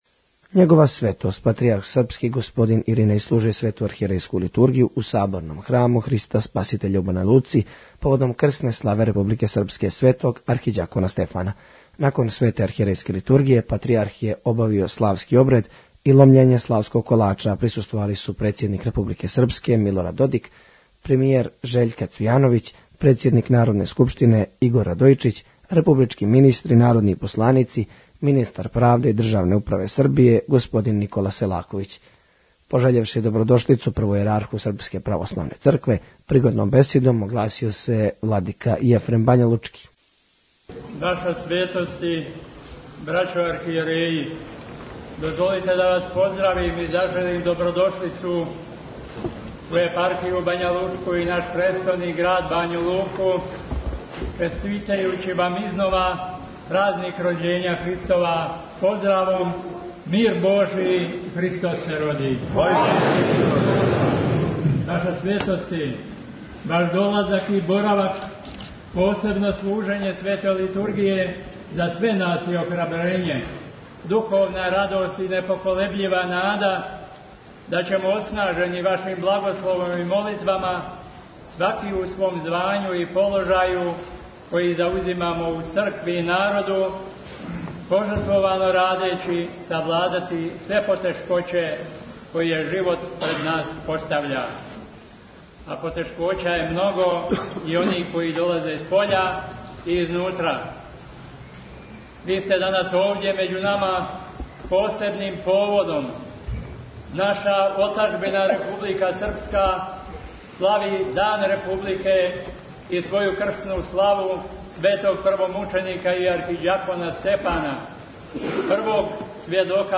Његова светост патријарх српски Иринеј служио је Свету архијерејску литургију у Саборном храму Христа Спаситеља у Бањалуци, поводом крсне славе Републике Српске Светог архиђакона Стефана.